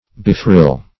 Meaning of befrill. befrill synonyms, pronunciation, spelling and more from Free Dictionary.
Befrill \Be*frill"\